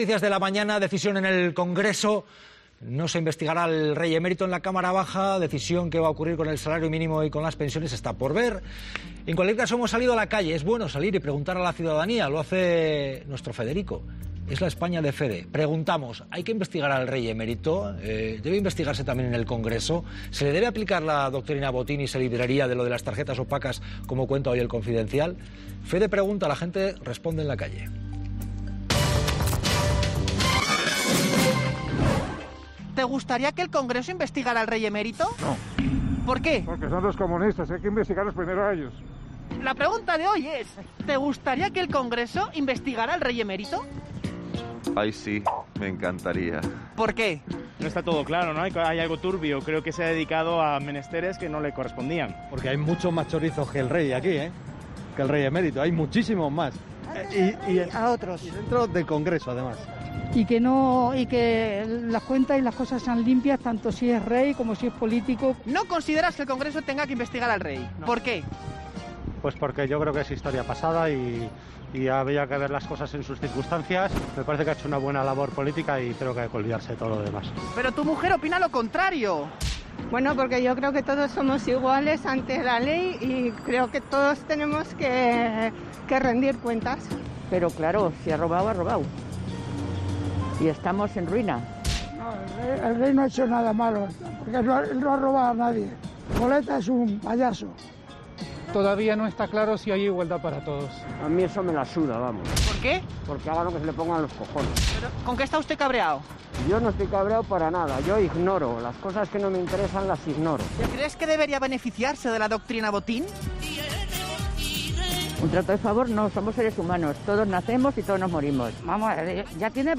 "Porque son los comunistas... Hay que investigarlos primero a ellos", ha sentenciado ante un reportero atónito que daba comienzo así a su reportaje por las calles de Madrid.
Reportaje Las Cosas Claras